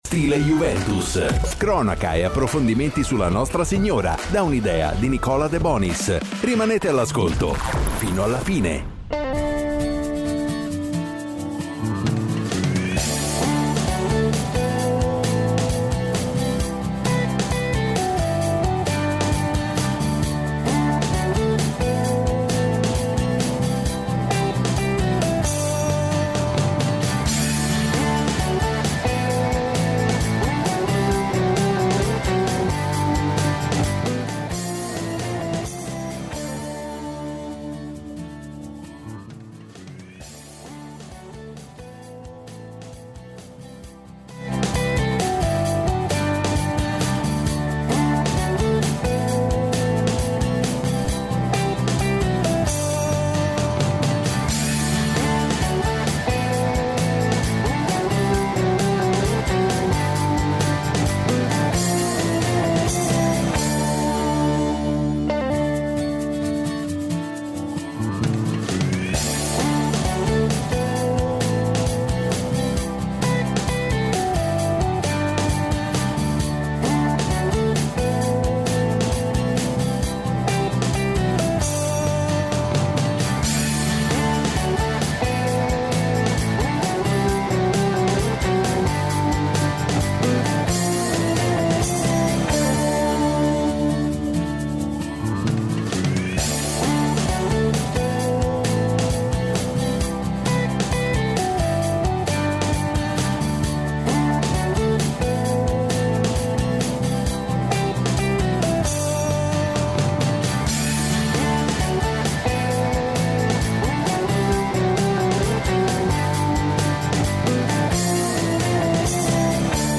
Intervenuto ai microfoni di Radio Bianconera